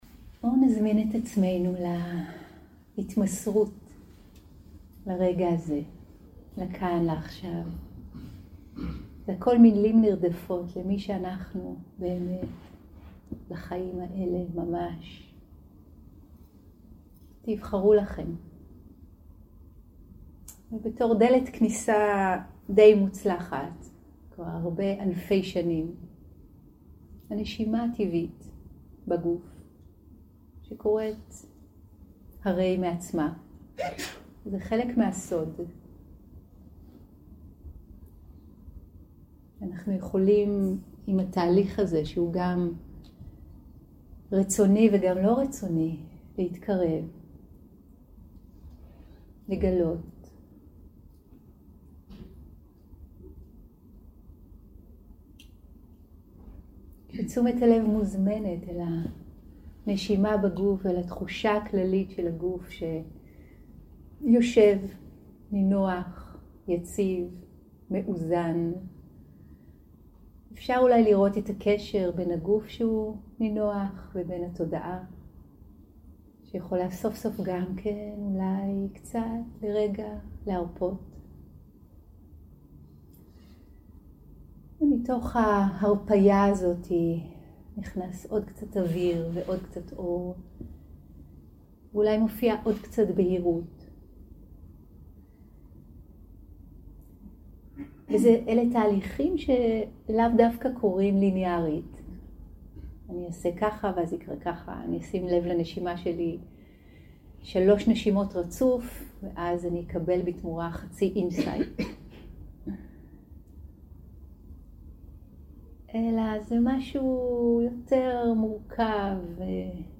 יום 2 – הקלטה 2 – בוקר – הנחיות למדיטציה – לשמוט את המיותר 0:00 0:00 סוג ההקלטה: Dharma type: Guided meditation שפת ההקלטה: Dharma talk language: Hebrew